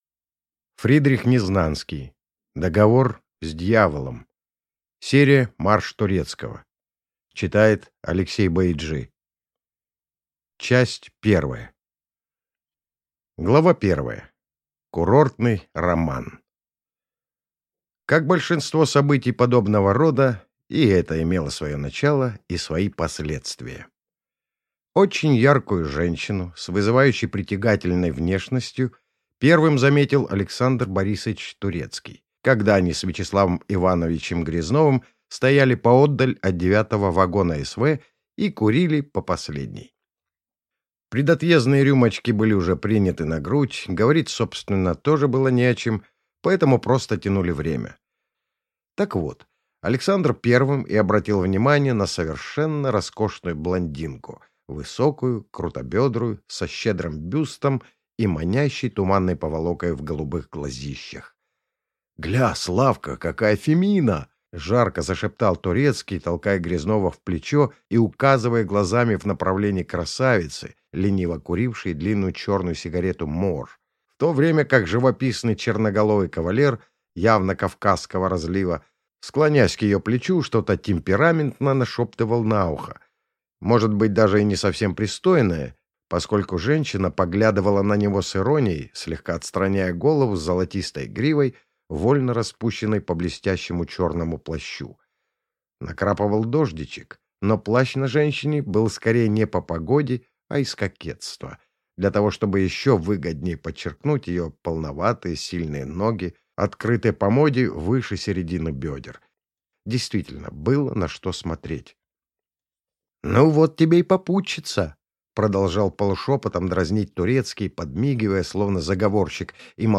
Аудиокнига Договор с дьяволом | Библиотека аудиокниг
Прослушать и бесплатно скачать фрагмент аудиокниги